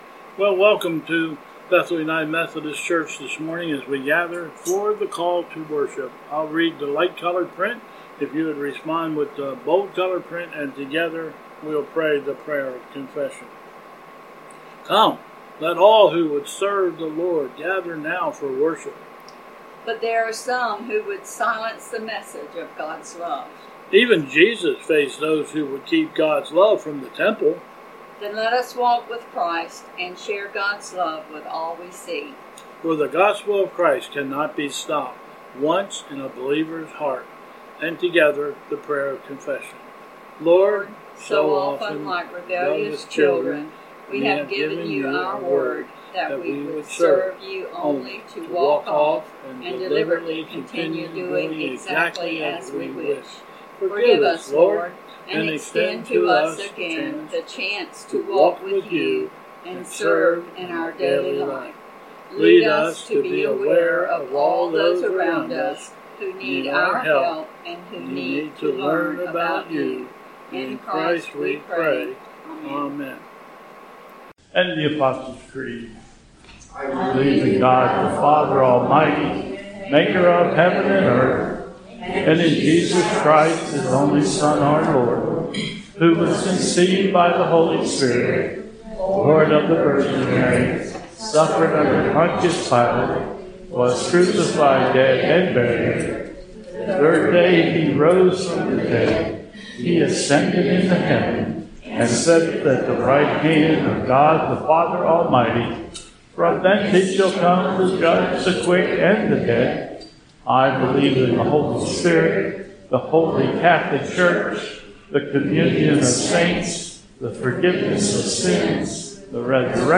Bethel 09/27/20 Service
Processional
Prelude/Processional